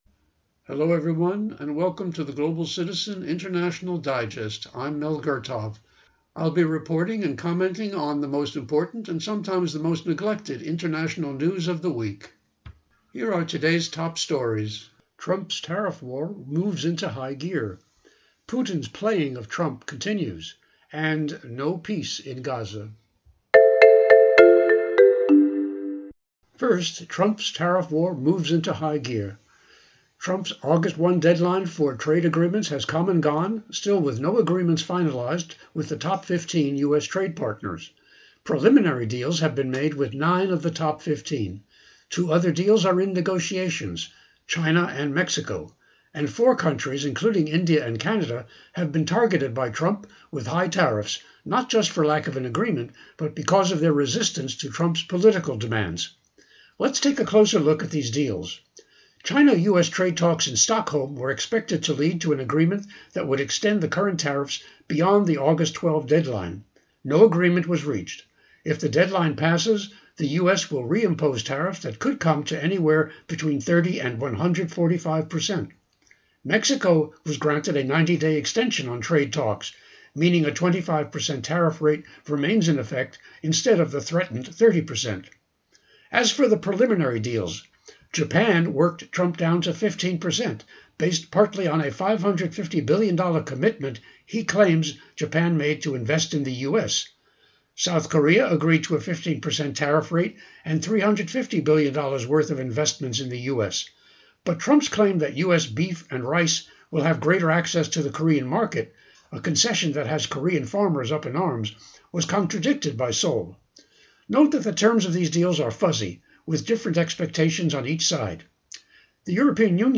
Genre(s): Public Affairs